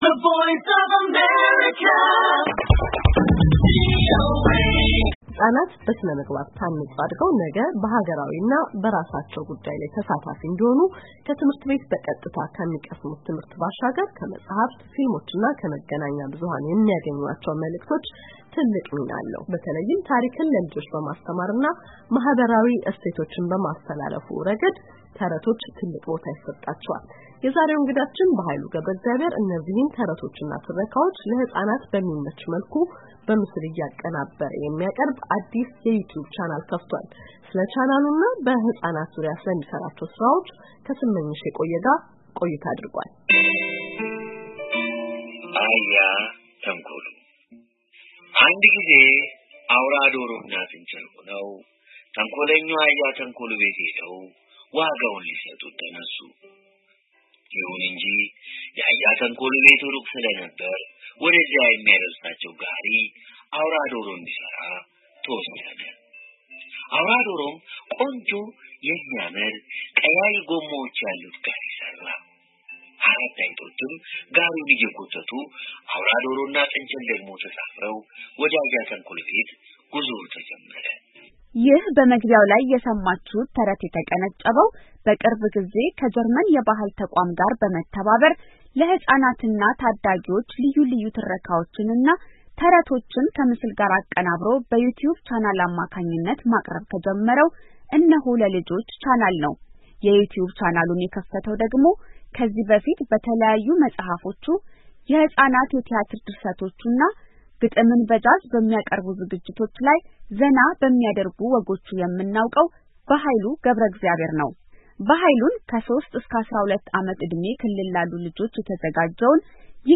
ይህ በመግቢያው ላይ የሰማችሁት ተረት የተቀነጨበው በቅርብ ጊዜ ከጀርመን የባህል ተቇም ጋር በመተባበር ለህፃናትና ታዲጊዎች ልዩ ልዩ ትረካዎችንና ተረቶችን ከምስል ጋር አቀናብሮ በዩቲዩብ ቻናል አማካኝነት ማቅረብ ከጀመረው 'እነሆ ለልጆች' ቻናል ነው።